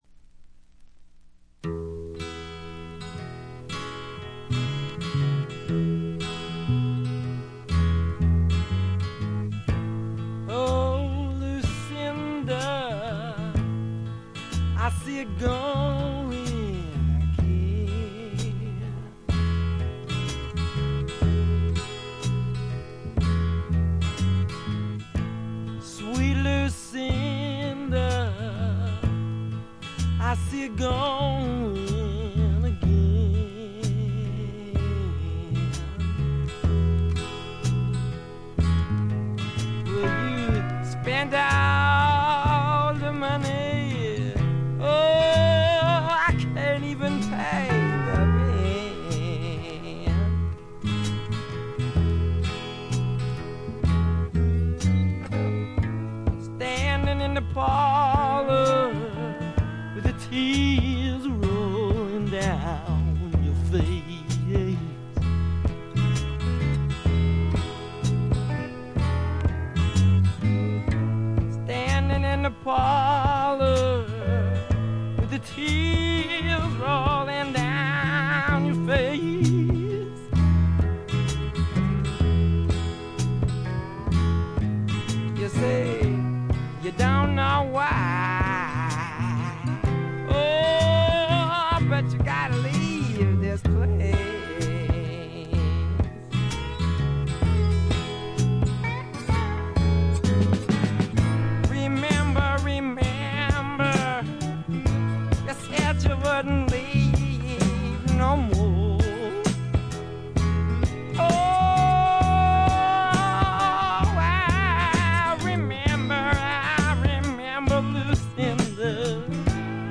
軽微なチリプチ少々、散発的なプツ音が少し。
まさしくスワンプロックの理想郷ですね。
試聴曲は現品からの取り込み音源です。
Vocals, Acoustic Guitar, Piano, Violin